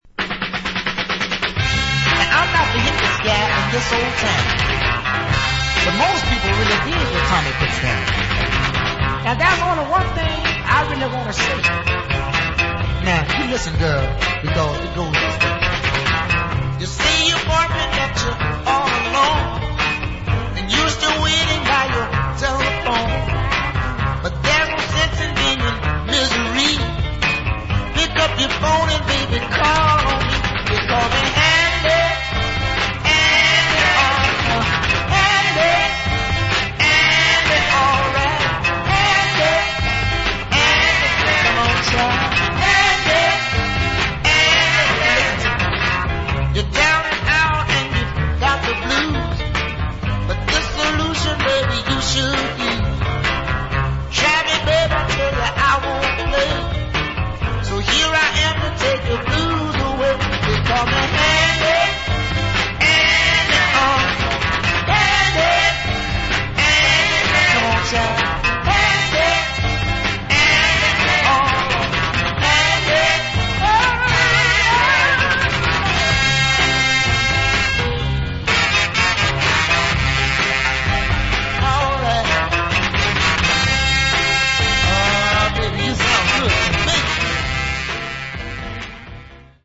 Quite a neat little 60's Northern Soul dancer